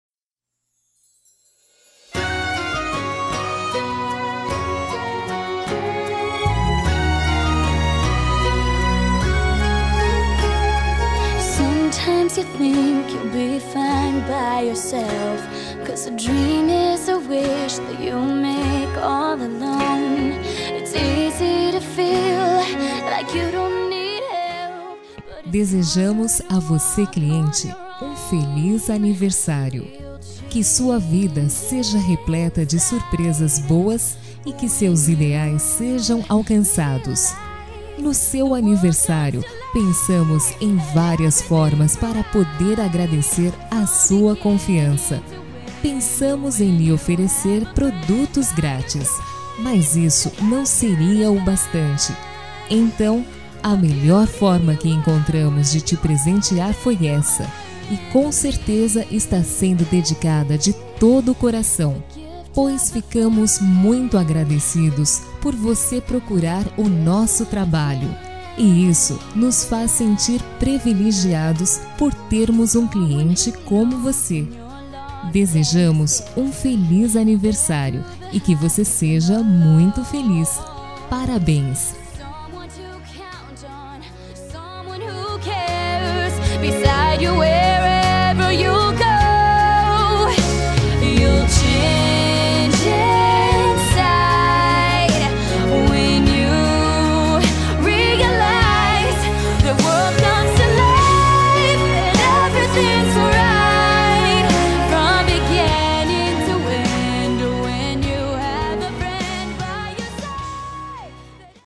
ANIVERSÁRIO CLIENTE
Voz Feminina
Código: 70401 – Música: Gift Of a Friend – Artista: Demi Lovato